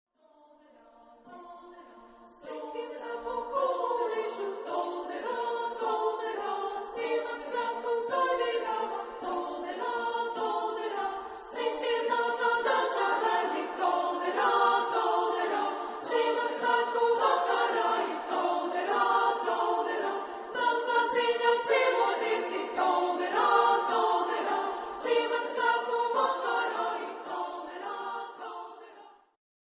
Genre-Style-Form: Christmas song ; Partsong
Mood of the piece: joyous ; lively
Type of Choir: SSA  (3 children OR women voices )
Instruments: Drum (1)
Tonality: B flat major ; G minor